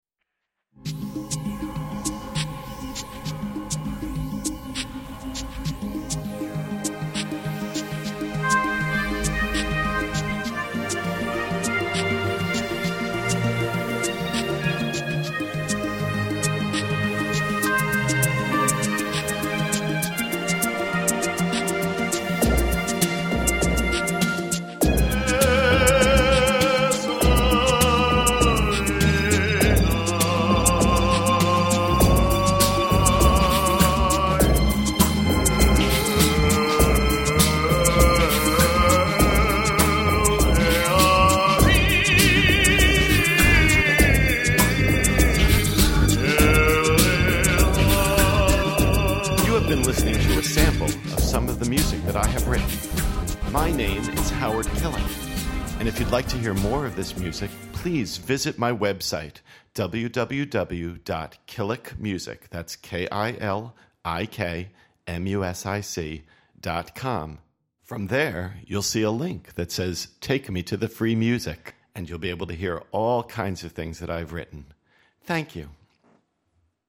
Then, to a videotape of his rehearsal, I wrote my own "techno" piece and started to weave the vocal line into the music.
The actual recording is entirely synthesized except for the vocal line - that's me chanting.